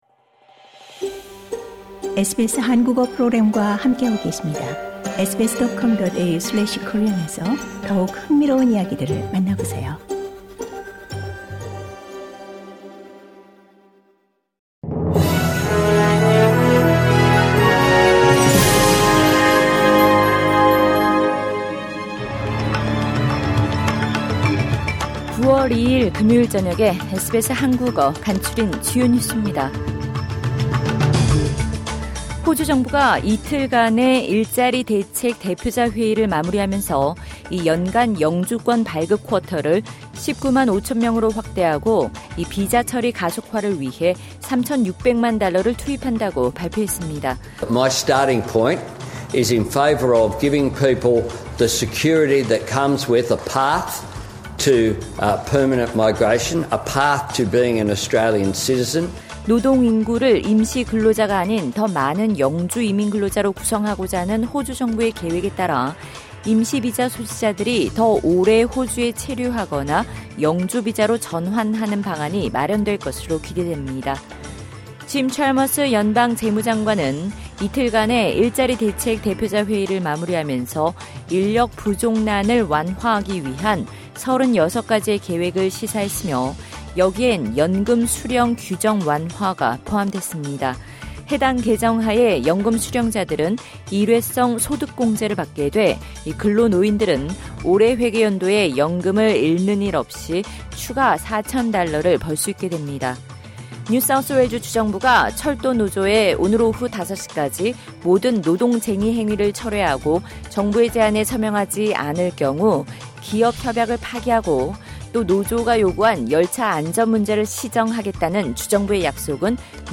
SBS 한국어 저녁 뉴스: 2022년 9월 2일 금요일
2022년 9월 2일 금요일 저녁 SBS 한국어 간추린 주요 뉴스입니다.